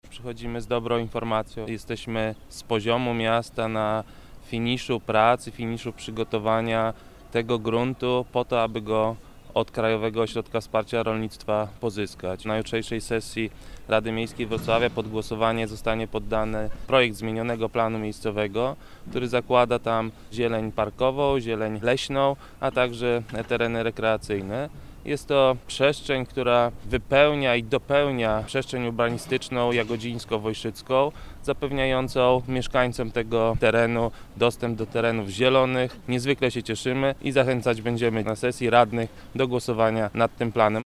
– Jesteśmy na finiszu przygotowań gruntu, by pozyskać go od Krajowego Ośrodka Wsparcia Rolnictwa – wyjaśnia Michał Młyńczak, wiceprezydent Wrocławia.